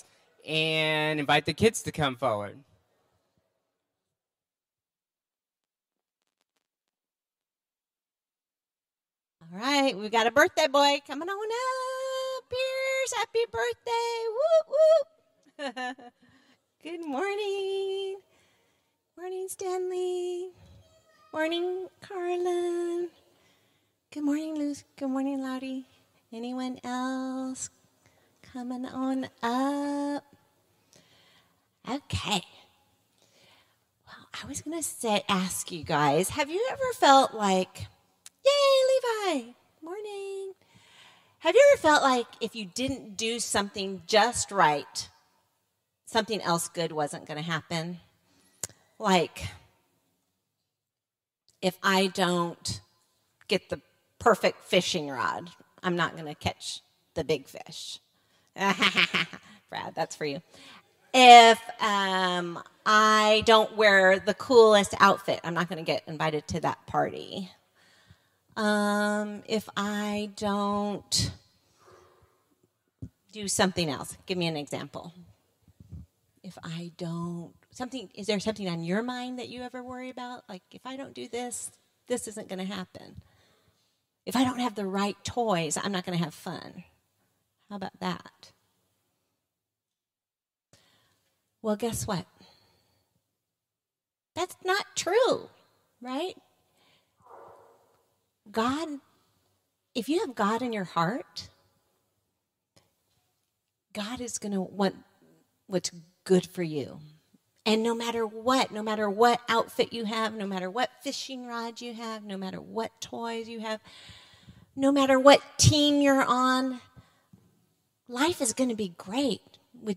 Children’s Time
Sermon Series